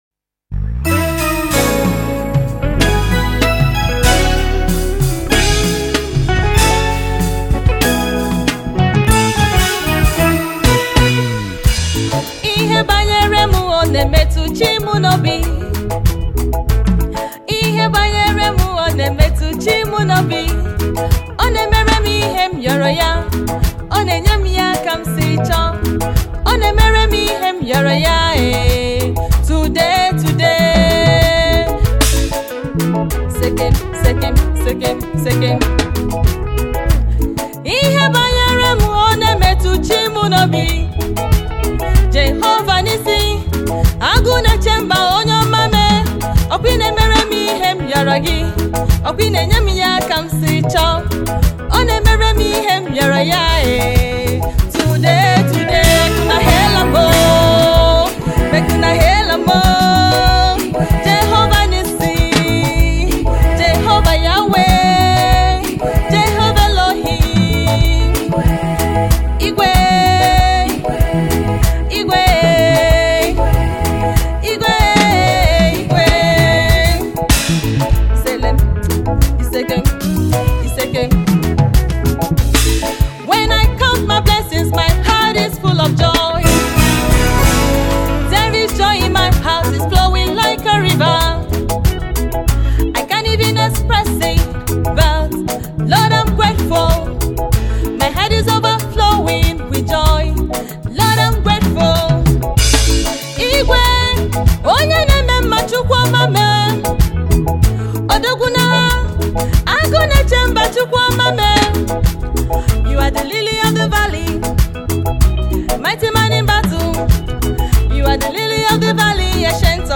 Crafted with heartfelt lyrics and uplifting melodies
soulful vocals